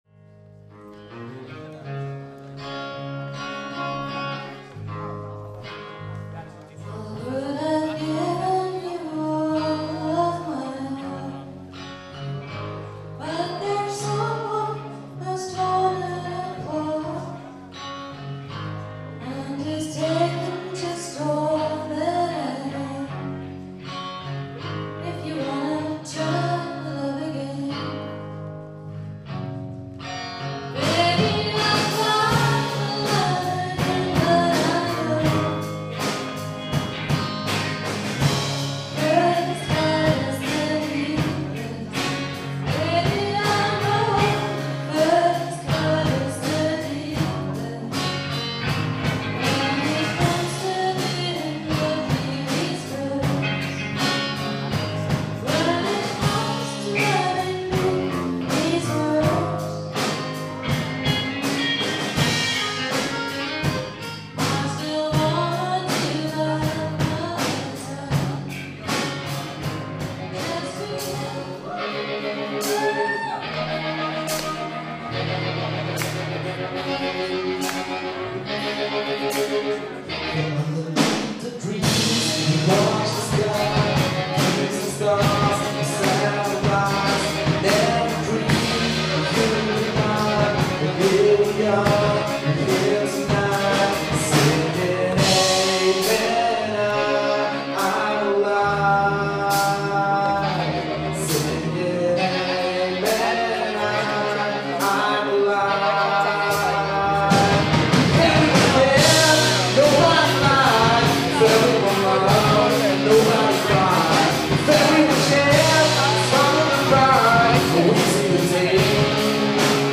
Rockkonzert an unserer Schule
Am Abend des 14.4.2011 gab es im Theatersaal von St. Georg ein Rockkonzert, bei dem Lehrer und Schüler gemeinsam mitwirkten.